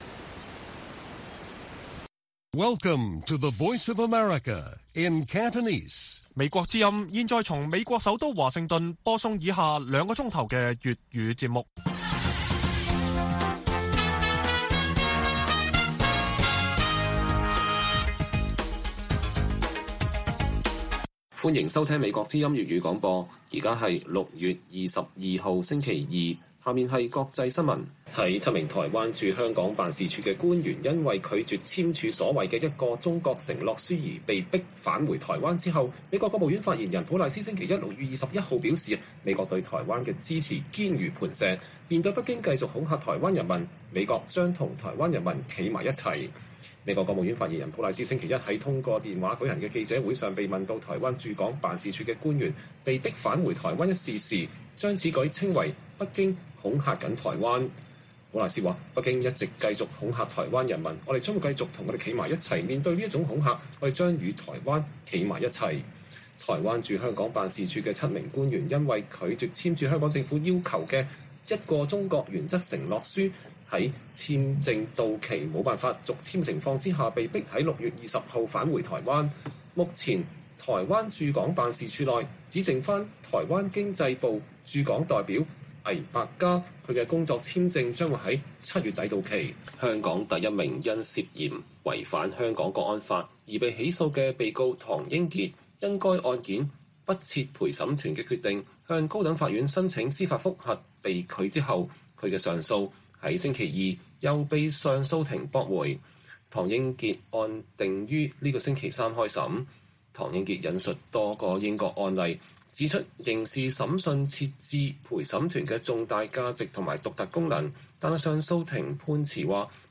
粵語新聞 晚上9-10點: 香港上訴庭維持國安法首名被告不設陪審團決定